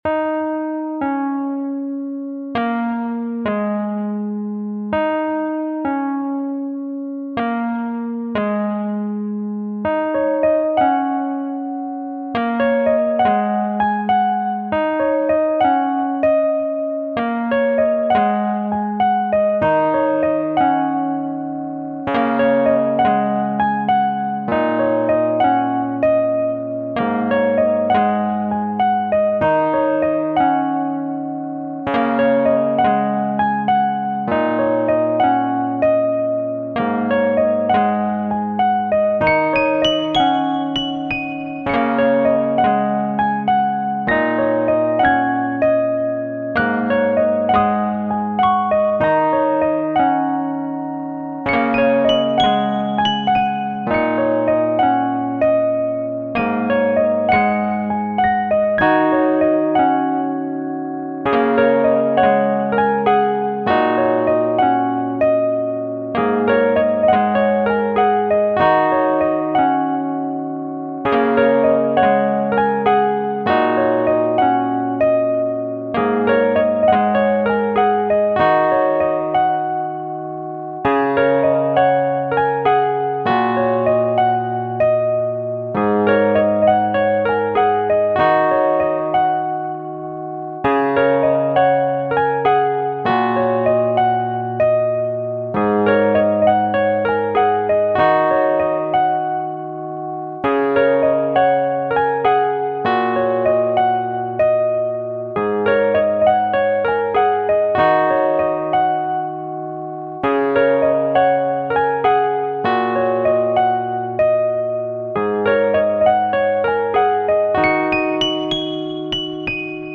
It's Still Safe To Go Outside (Mp3) I just got my keyboard for christmas and figure i should kick it off with a piano. Fortunately it ended up filling the whole track.